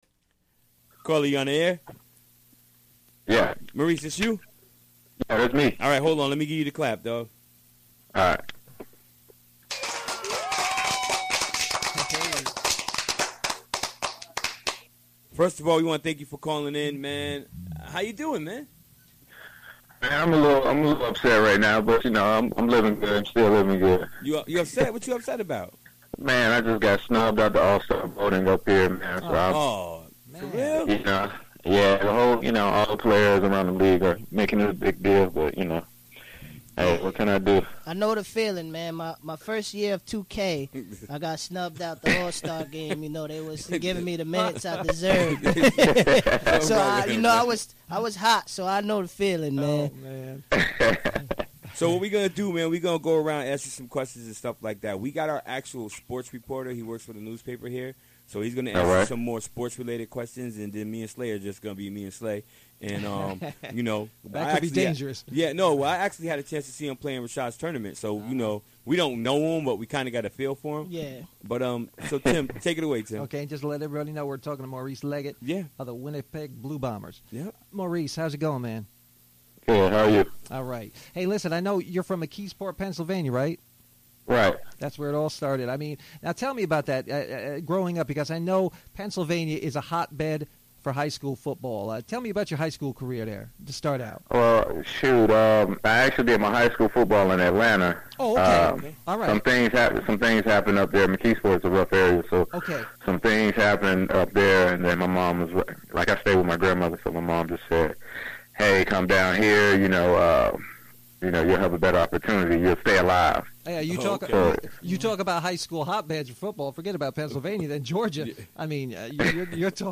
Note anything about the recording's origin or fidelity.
Recorded during the WGXC Afternoon Show Wednesday, December 7, 2016.